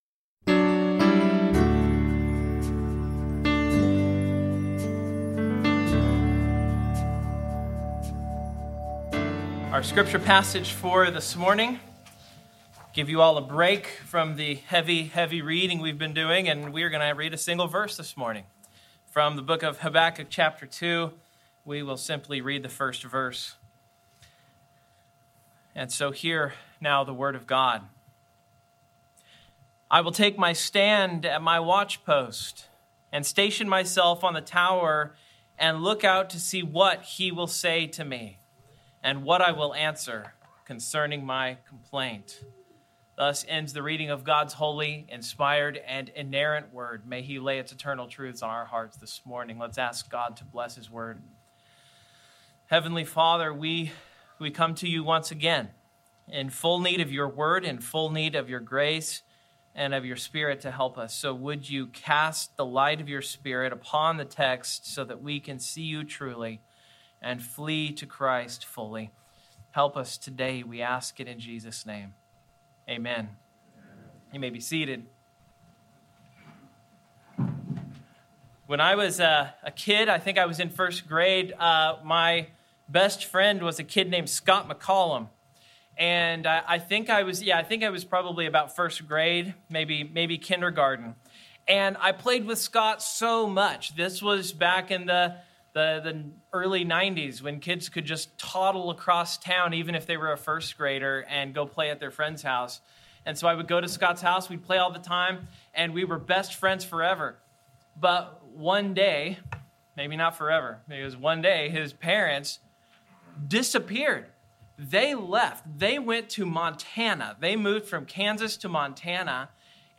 Habakkuk 2:1 Service Type: Morning Outline